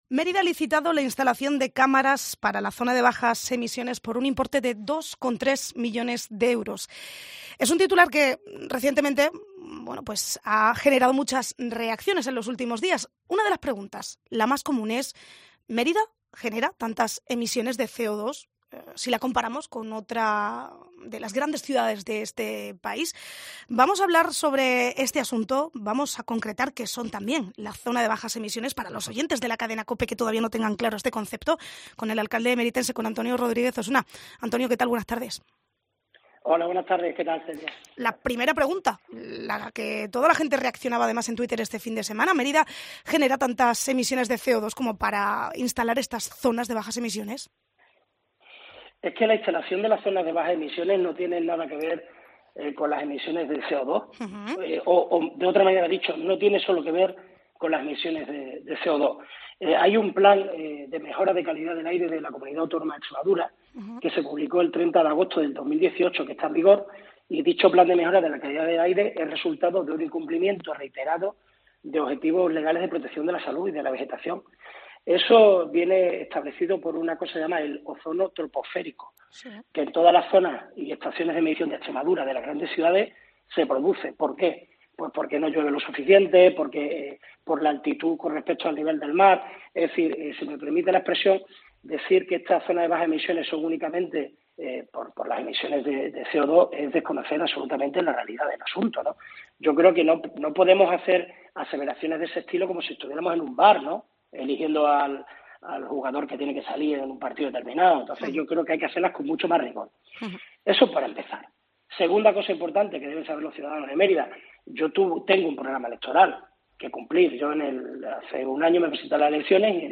Así se ha pronunciado en COPE el alcalde de Mérida, Antonio Rodríguez Osuna, sobre la reciente licitación de cámaras en Zonas de Bajas Emisiones.